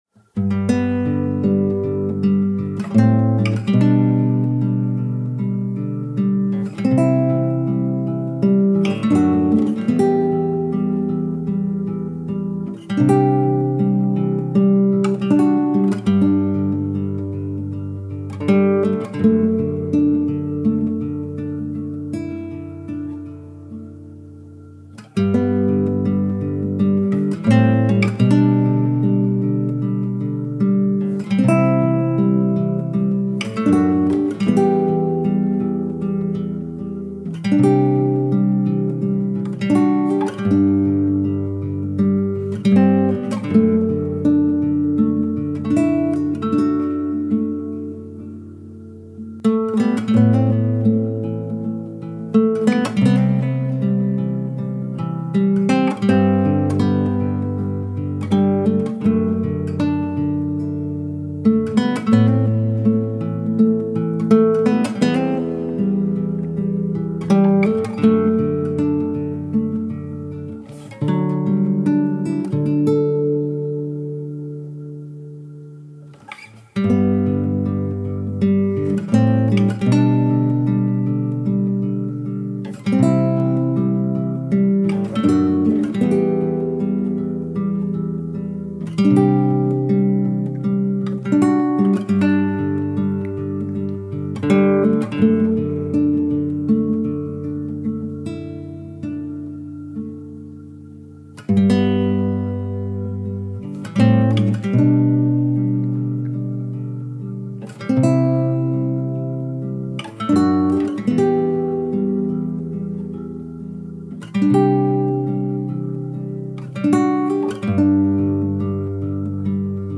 آرامش بخش , عاشقانه , عصر جدید , گیتار , موسیقی بی کلام
گیتار آرامب گیتار عاشقانه موسیقی بی کلام نیو ایج